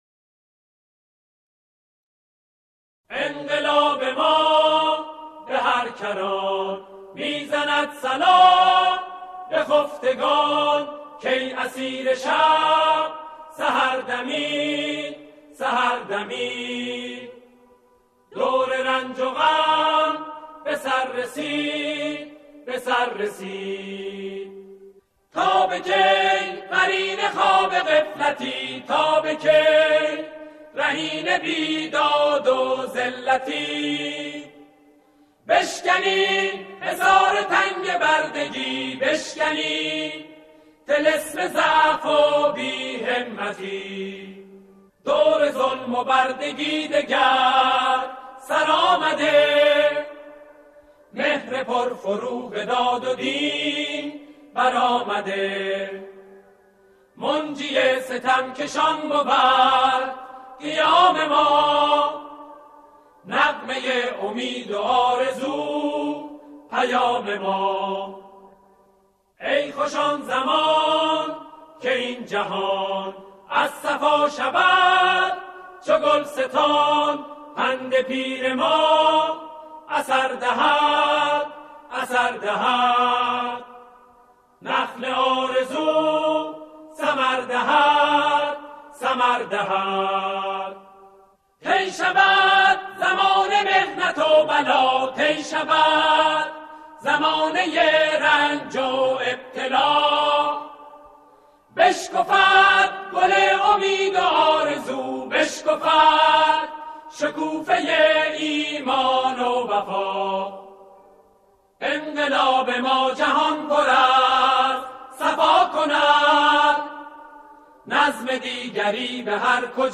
آکاپلا